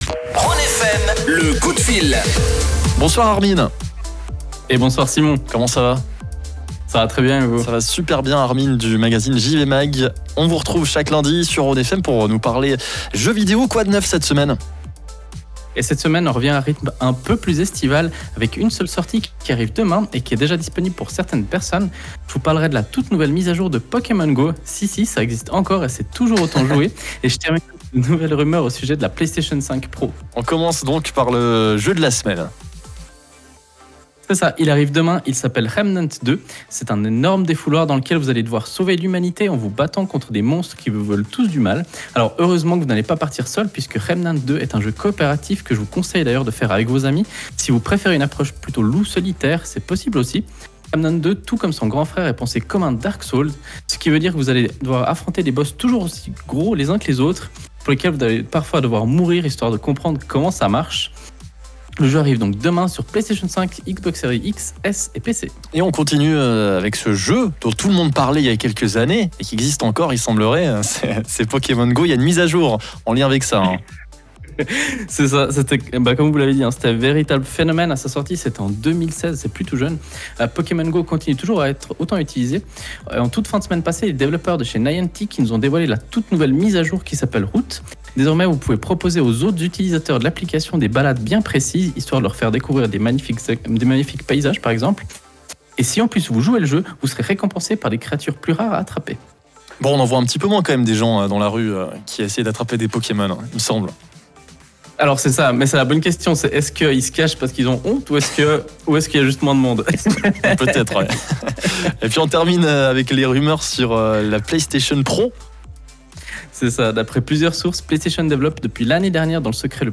Vous pouvez réécouter le direct du jour via le flux qui se trouve juste au dessus.